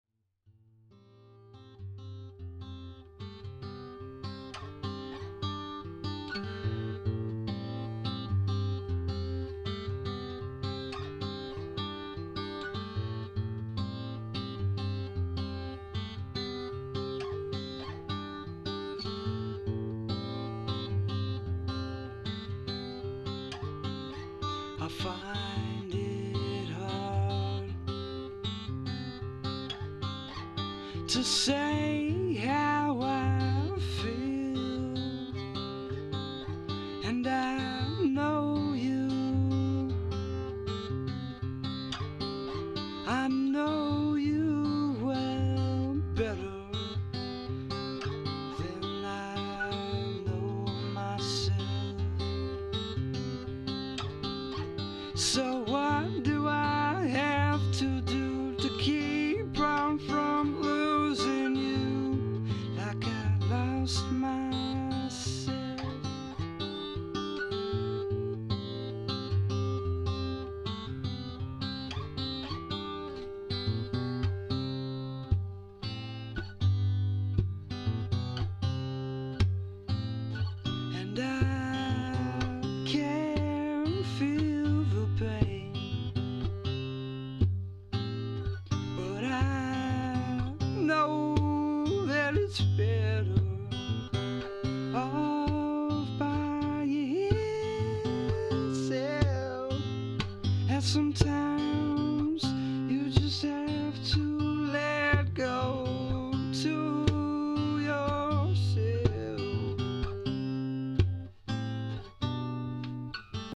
A mellow guitar tune